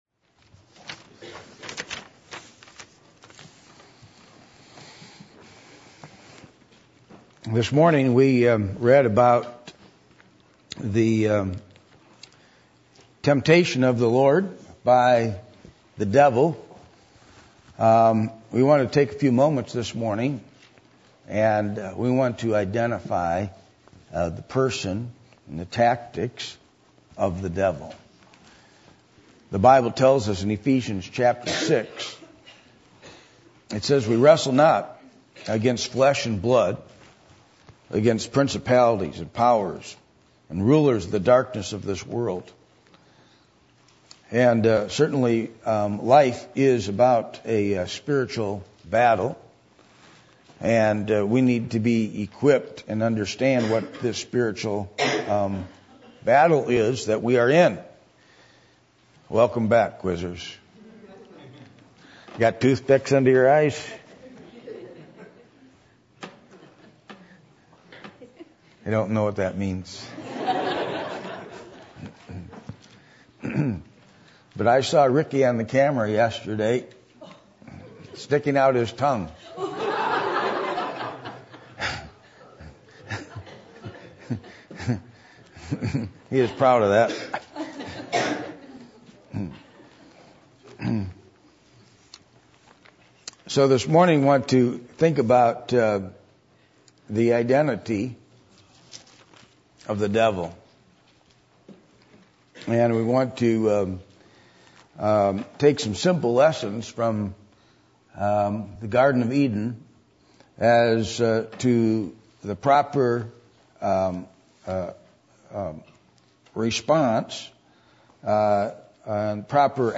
Passage: Luke 4:1-13 Service Type: Sunday Morning %todo_render% « The Importance of Prayer in a Church How Is The Work Of The Lord Going To Get Done?